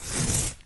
calligraphy.ogg